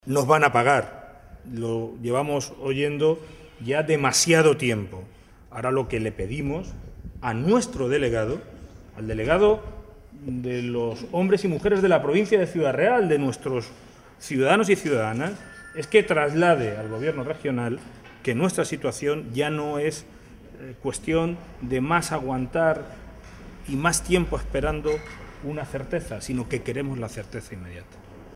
Audio alcalde Puertollano-3